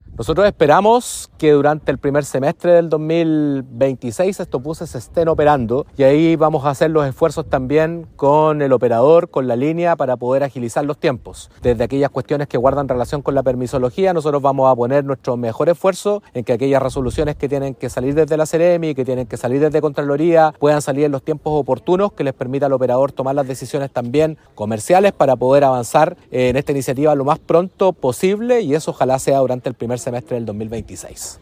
En tanto, el seremi de Transportes, Patricio Fierro, informó que la empresa recibirá $660 millones de pesos adicionales al año como parte del subsidio que entrega el ministerio por la incorporación de estos buses eléctricos.